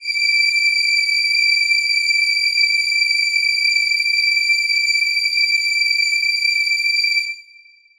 Choir Piano
D7.wav